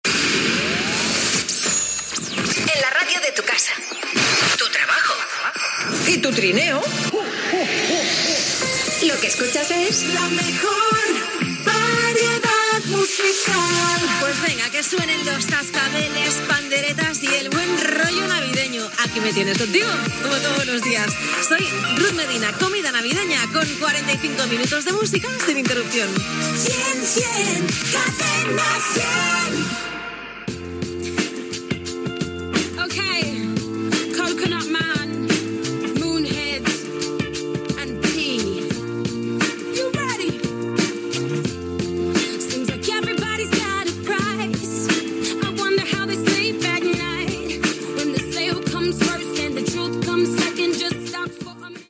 Presentació, indicatiu i tema musical Gènere radiofònic Musical